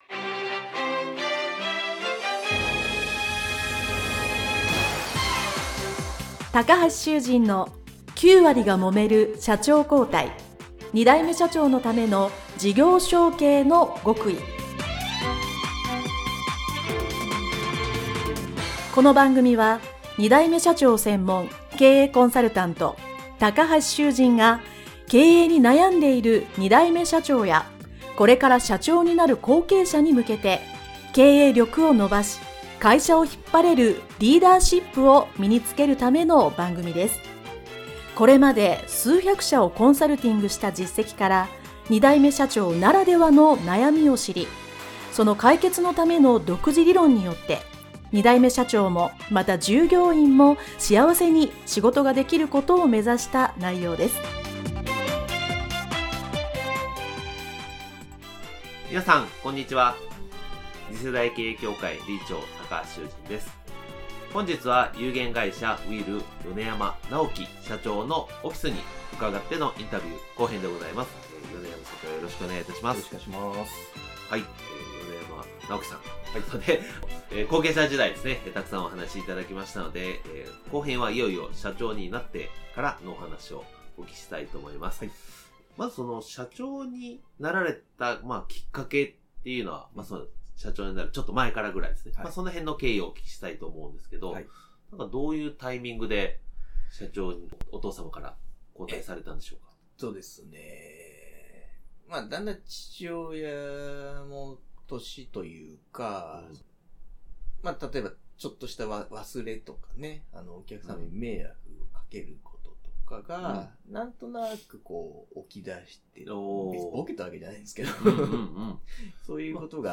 インタビュー後編